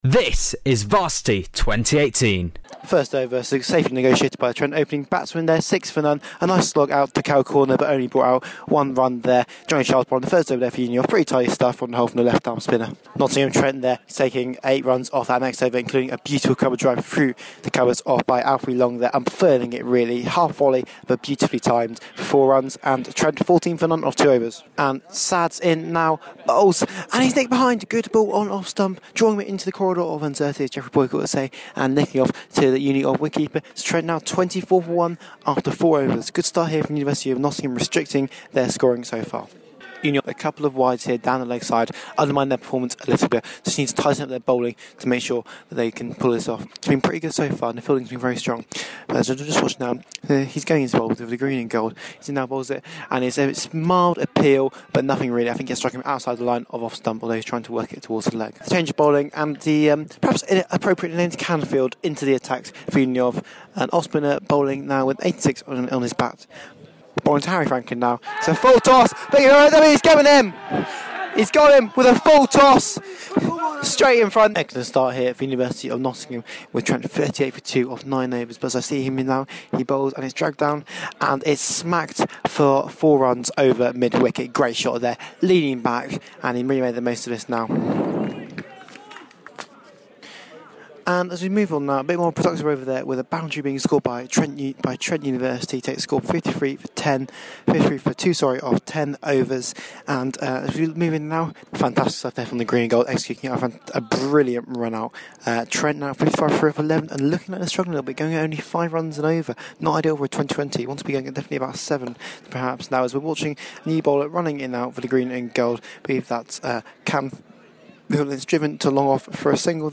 Catch our highlights of Varsity Men's Cricket 2018, as the University of Nottingham took on Nottingham Trent University. With the series evenly poised at 3-2 to UoN, victory was imperative for both sides.